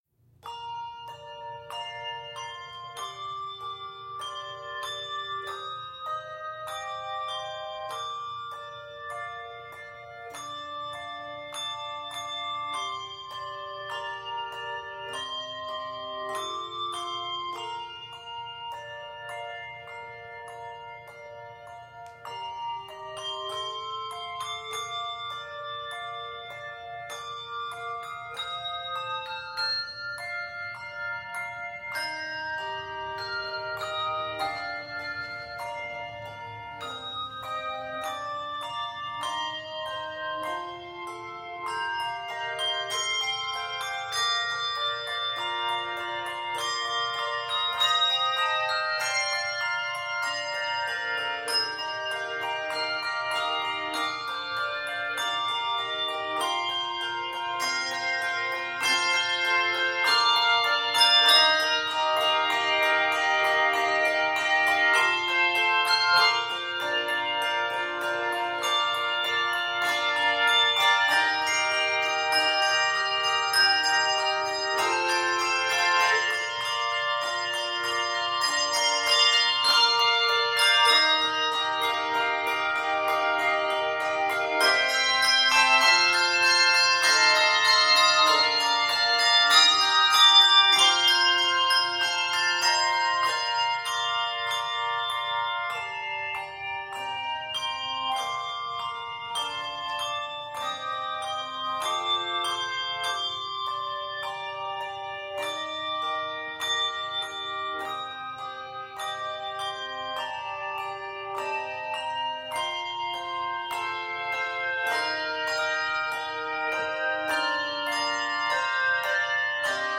it is arranged in the keys of C Major, D Major, and Eb Major